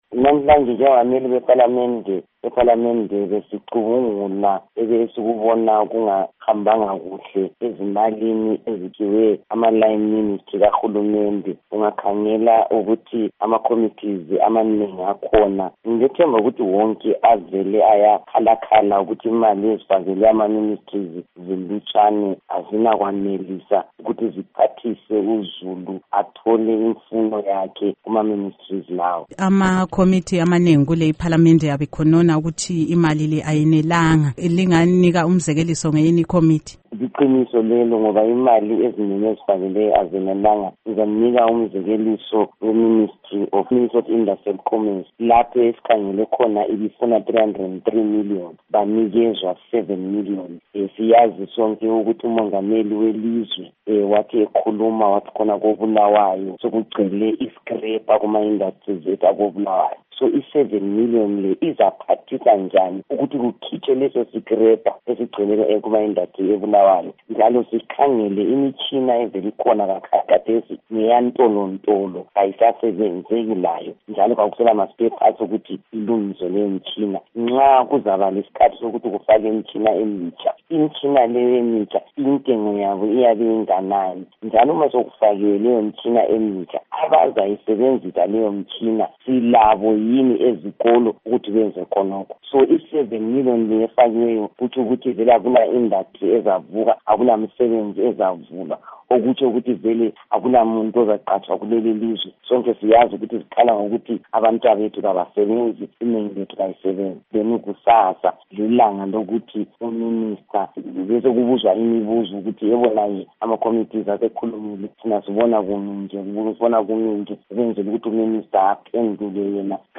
Ingxoxo LoNkosazana Thabitha Khumalo